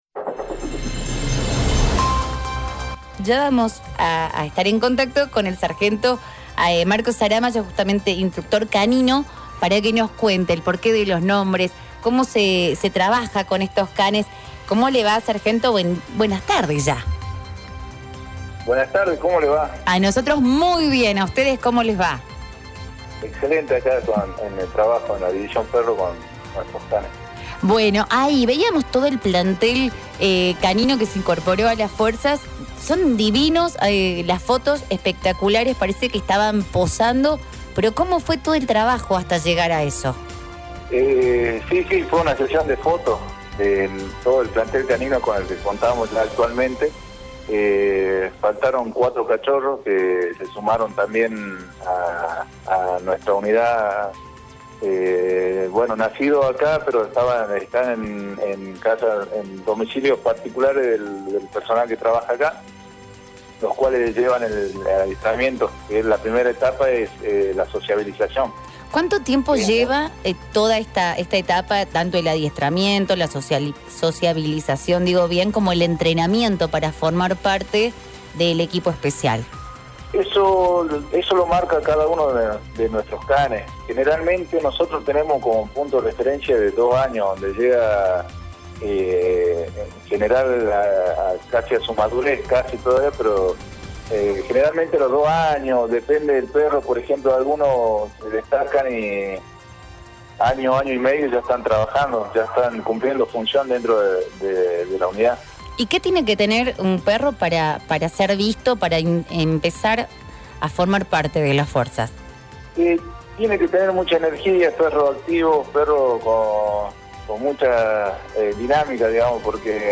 en diálogo con La Mañana de City comentó cómo es la selección de los perros, su entrenamiento y por supuesto el momento de la jubilación.